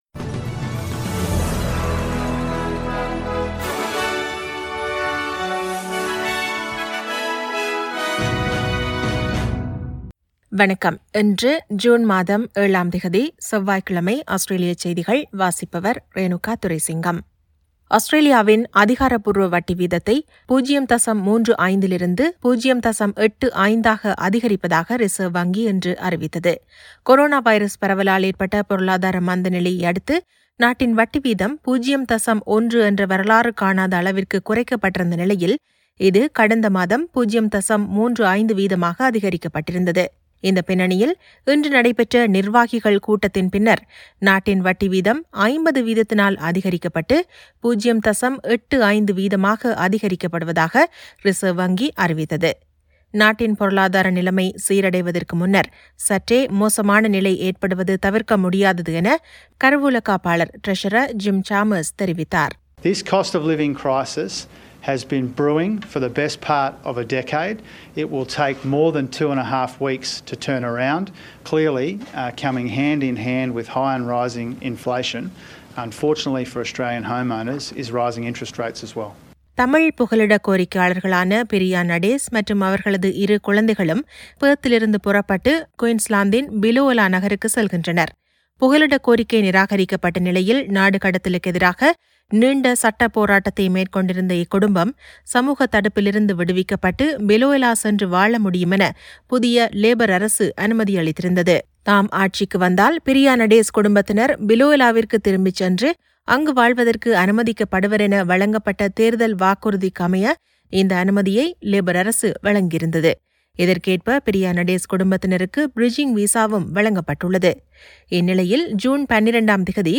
Australian news bulletin for Tuesday 07 June 2022.